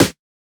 Index of /99Sounds Music Loops/Drum Oneshots/Twilight - Dance Drum Kit/Snares